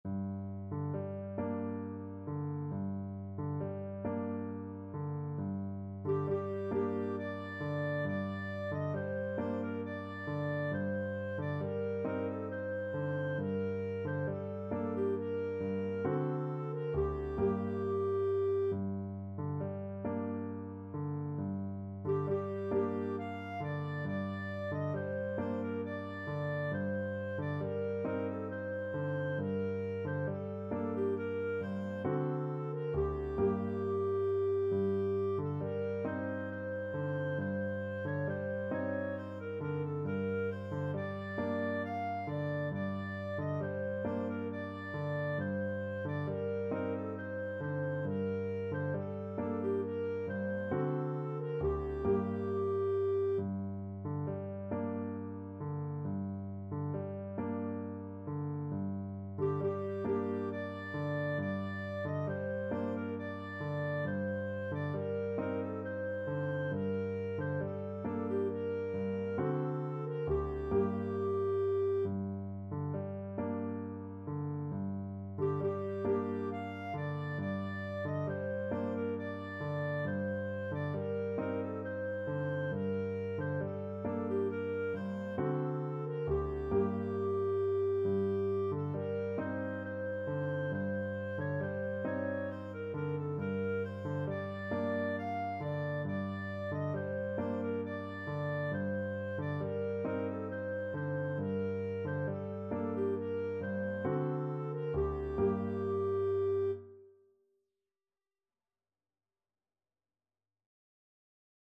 Clarinet
Gently rocking .=c.45
G minor (Sounding Pitch) A minor (Clarinet in Bb) (View more G minor Music for Clarinet )
6/8 (View more 6/8 Music)
Turkish